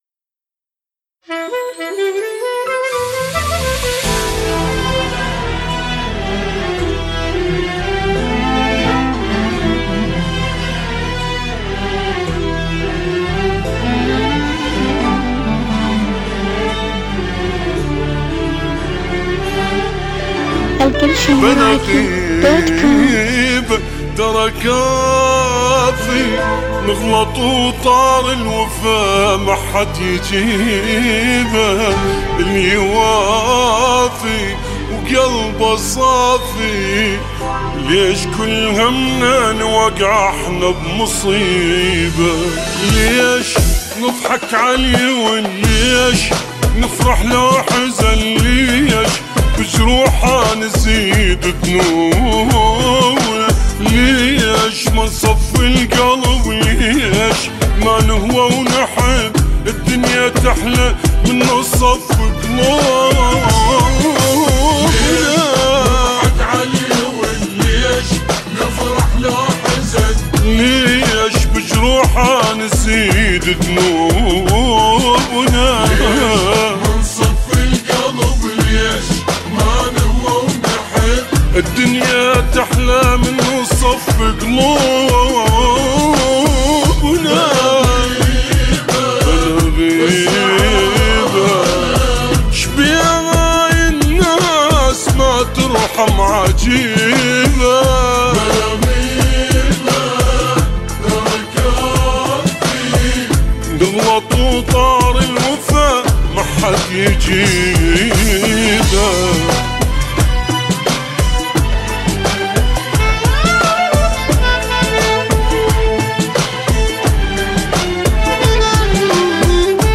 اغاني عراقيه 2017